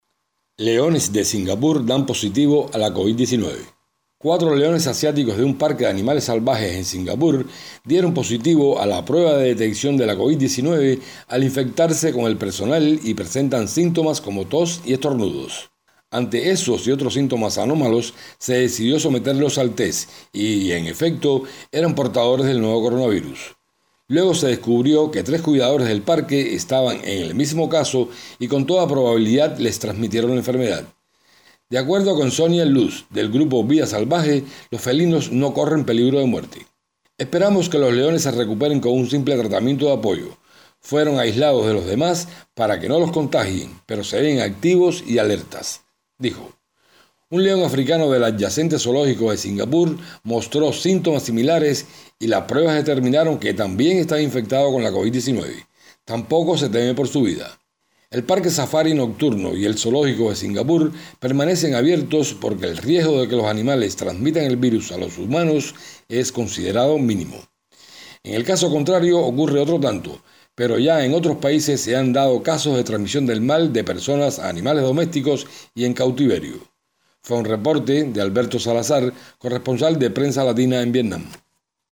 desde Hanoi.